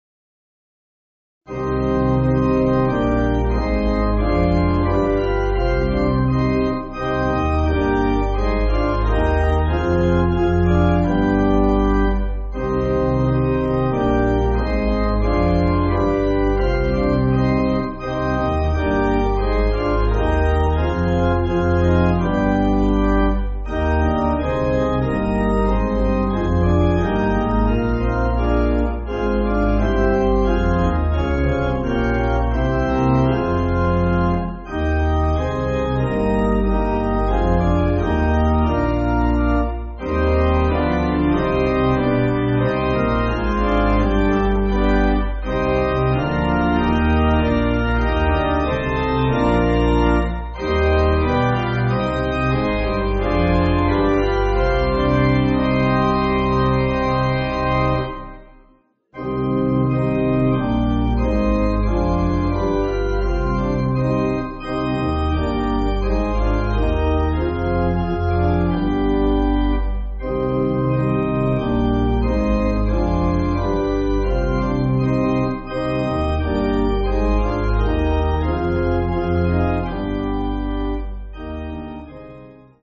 Organ
(CM)   4/Cm